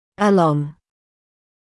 [ə’lɔŋ][э’лон]вдоль, параллельно